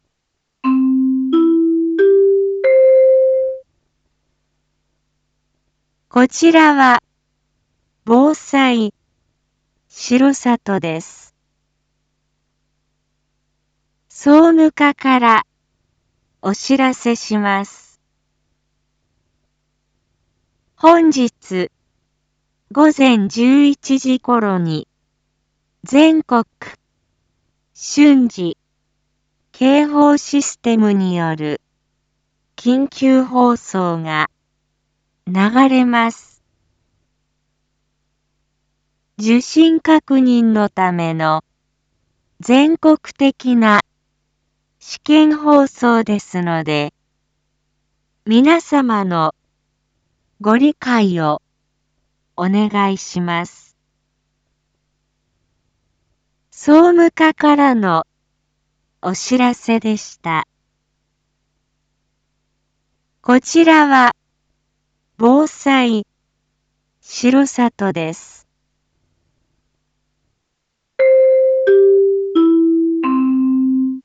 Back Home 一般放送情報 音声放送 再生 一般放送情報 登録日時：2022-11-16 07:01:15 タイトル：Jアラート訓練放送について インフォメーション：こちらは防災しろさとです。